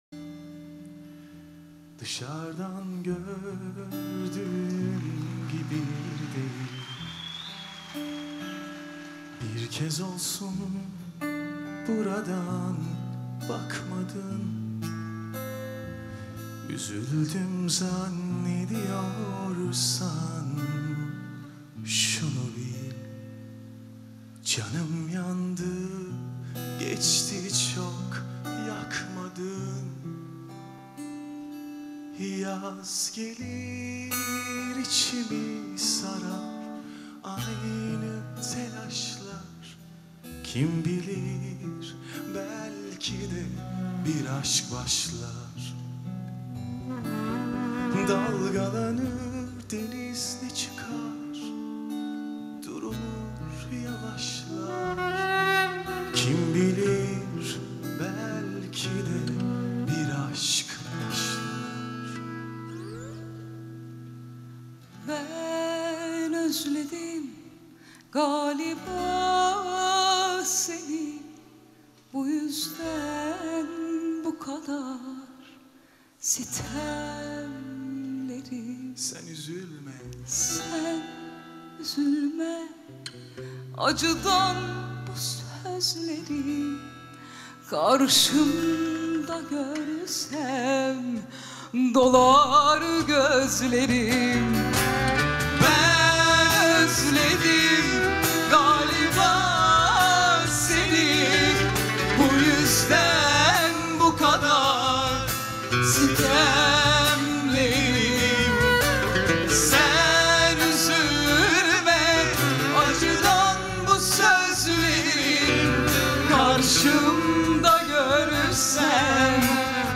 اجرای زنده